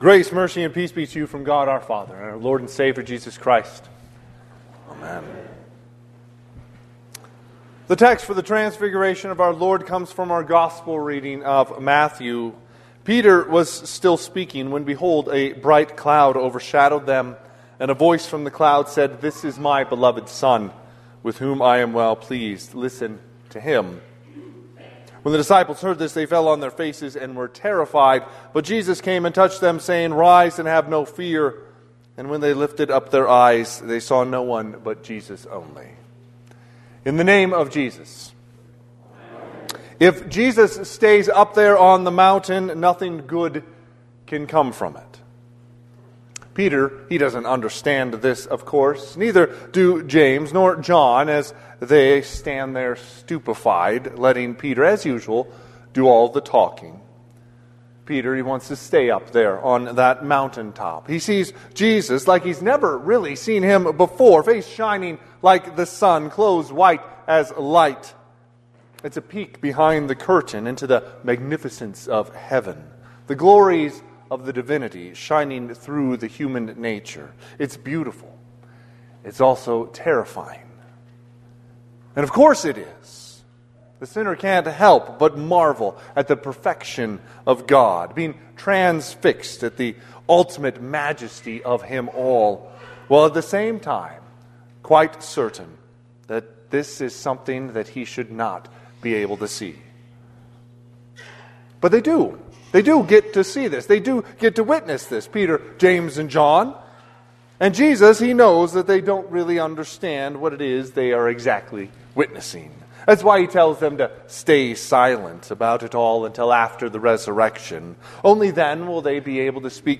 Sermon - 2/15/2026 - Wheat Ridge Evangelical Lutheran Church, Wheat Ridge, Colorado